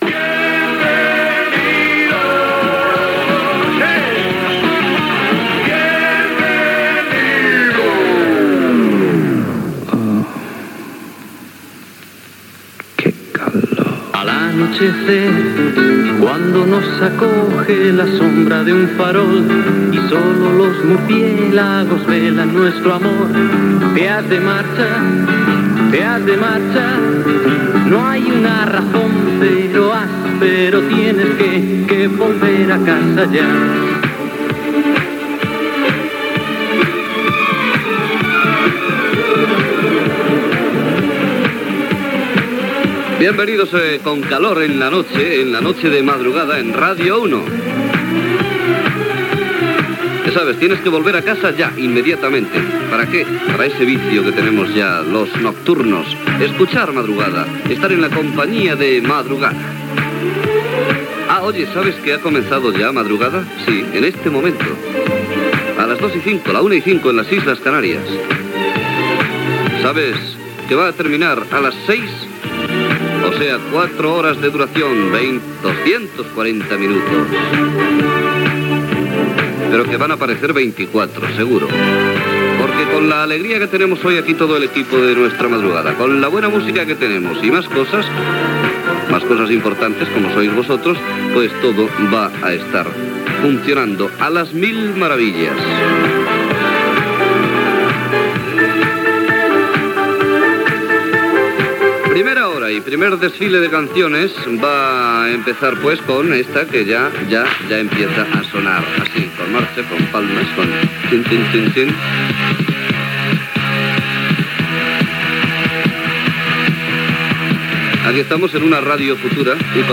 Careta del programa, presentació, hora, comentari i tema musical
Musical